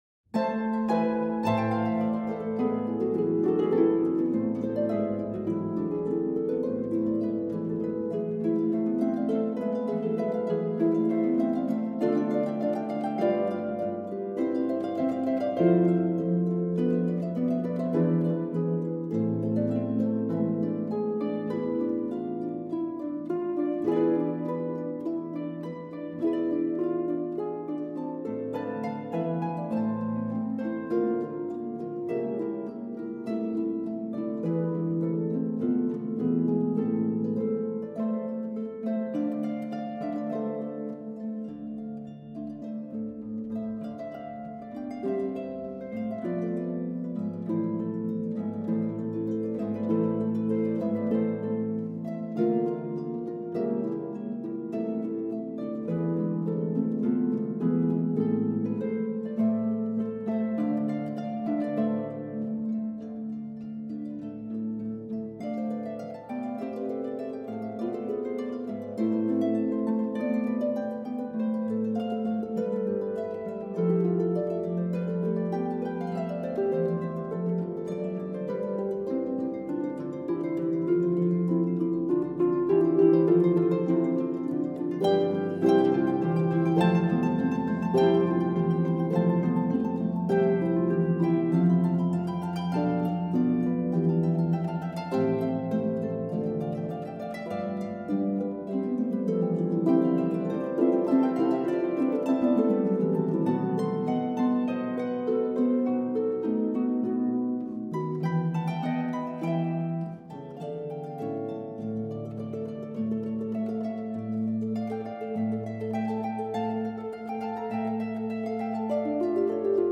for four pedal harps.